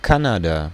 Ääntäminen
Canada: IPA : [ˈkæn.ə.də]